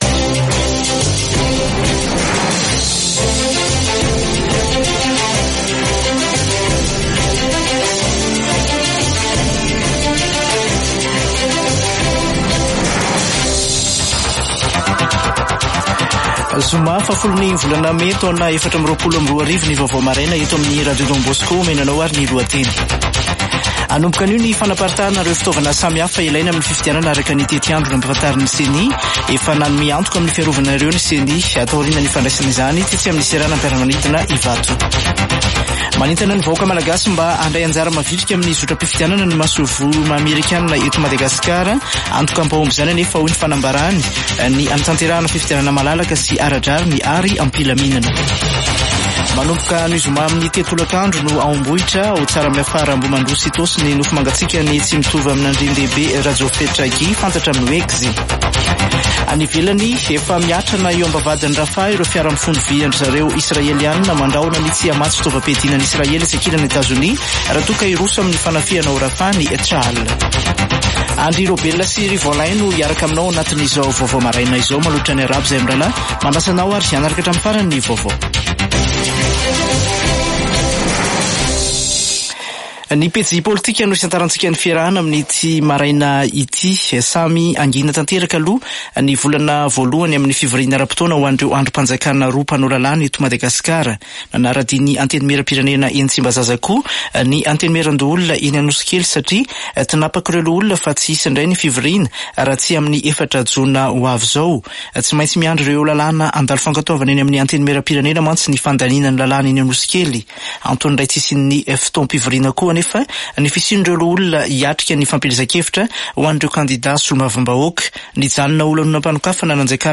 [Vaovao maraina] Zoma 10 mey 2024